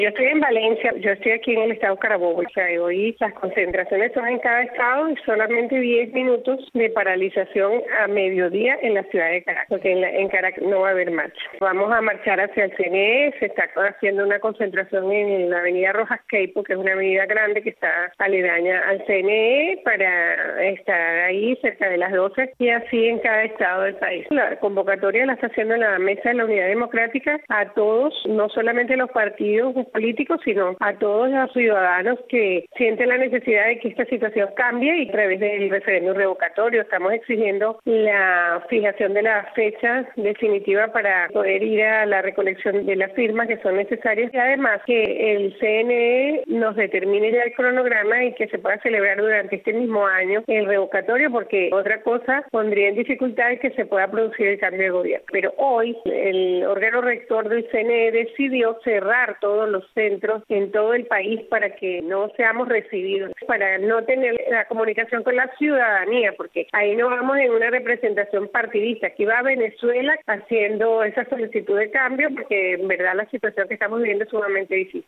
Vestalia Araujo, ex diputada opositora, ofreció detalles de las concentraciones a Radio Martí.